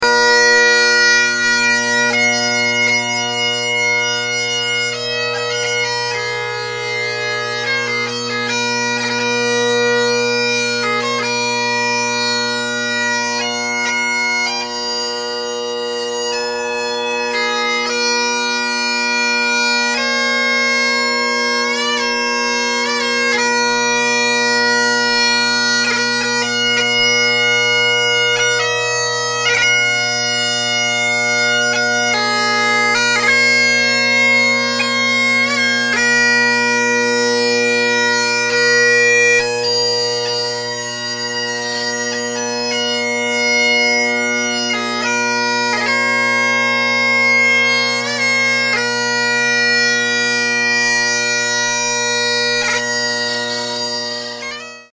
Kron Heritage (=Henderson) + full cane drone reeds
Drone Sounds of the GHB
Audacity tells me a pitch of low A ~ 479 Hz.
2005 Kron Heritage (these have the newest bores; the original Heritage stands had problems with a rough sometimes growly bass, this was corrected in 2004/2005; my set has these changes)
Begg sheepskin bag, full set of cane drone reeds, McC2 with Higgins reed, overall pitch should be around 478. Recorded with a Zoom H2 in a medium sized bedroom.
This is what I would characterize as a classic big bold Henderson sound.